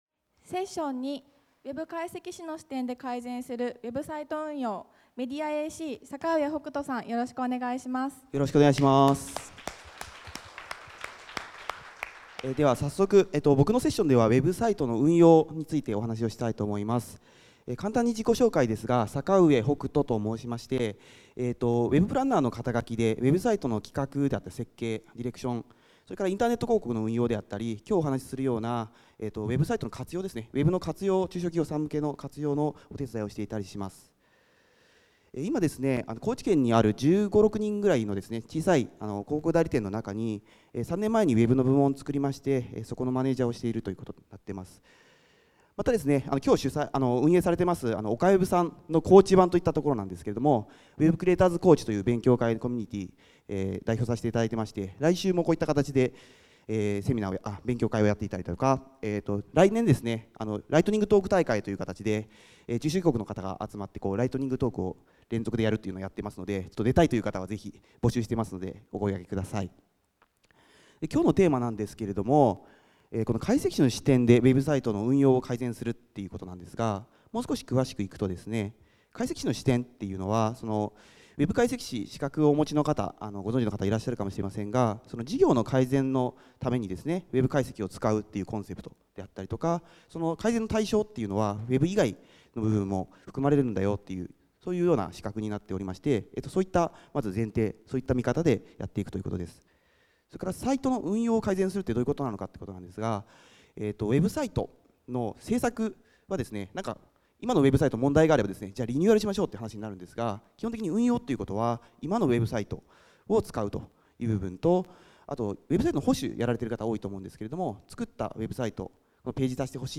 短時間に詰め込んだ＆早口で駆け抜けてしまったため、お聞き苦しい点もあったかと思いますが、皆さんのコメントやお声がけが大変励みになりました。